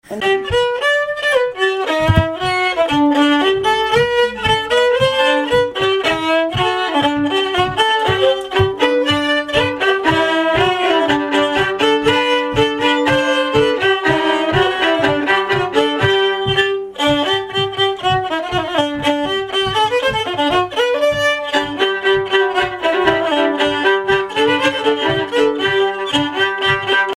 danse : polka
Pièces instrumentales à plusieurs violons
Pièce musicale inédite